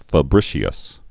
(fə-brĭshē-əs, -brĭshəs)